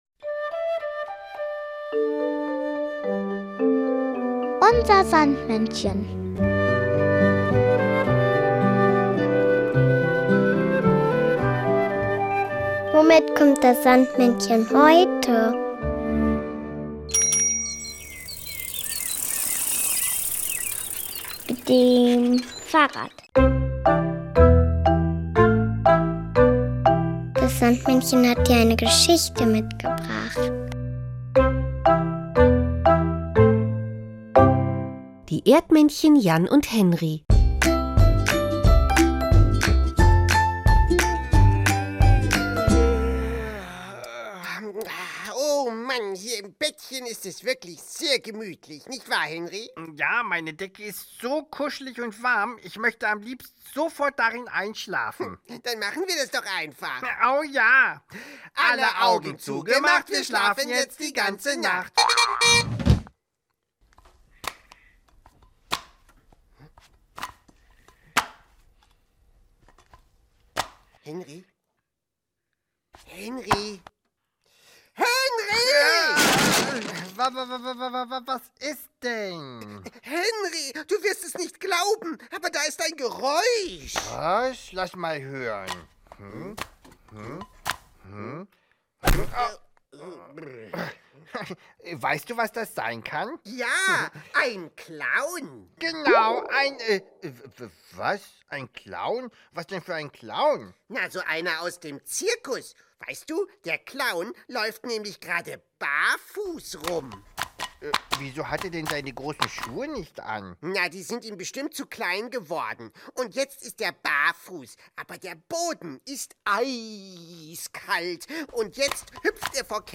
nur diese Geschichte mitgebracht, sondern auch noch das Kinderlied